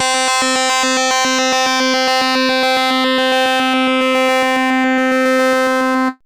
RANDOM FM 2.wav